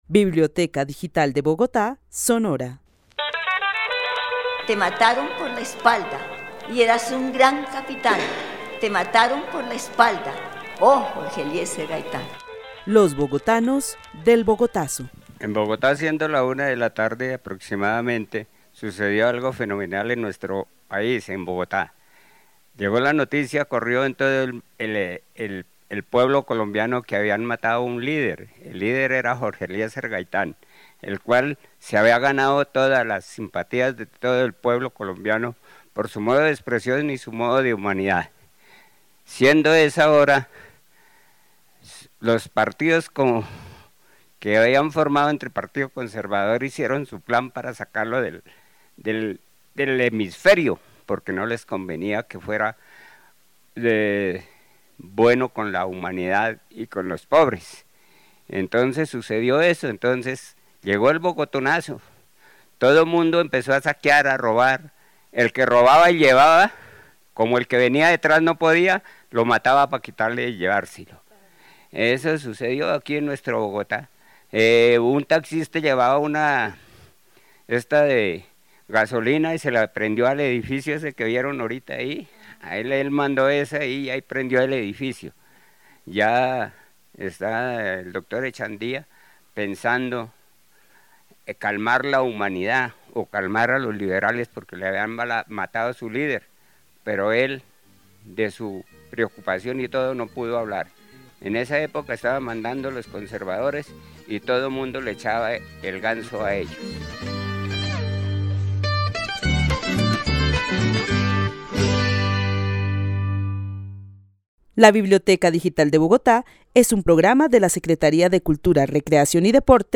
Narración oral de los hechos sucedidos el 9 de abril de 1948.
El testimonio fue grabado en el marco de la actividad "Los bogotanos del Bogotazo" con el club de adultos mayores de la Biblioteca El Tunal.